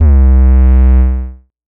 SOUTHSIDE_808_earbreaker_C.wav